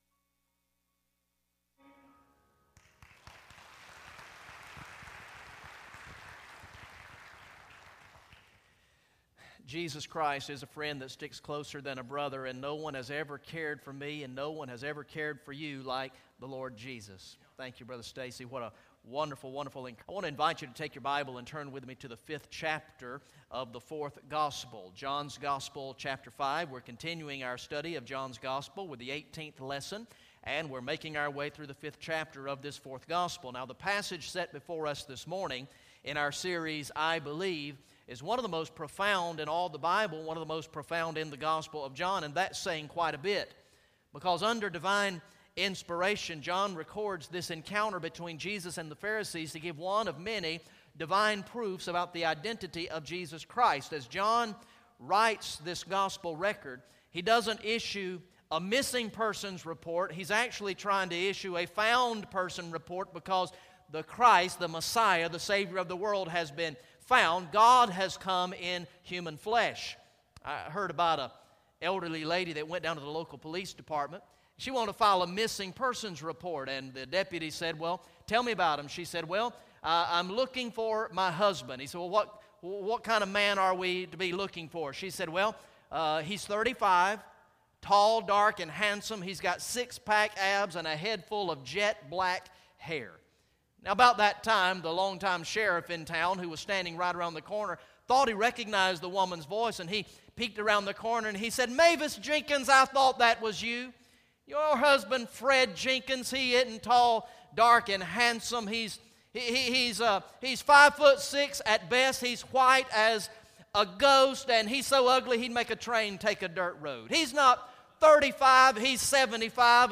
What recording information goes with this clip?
Message #18 from the sermon series through the gospel of John entitled "I Believe" Recorded in the morning worship service on Sunday, August 10, 2014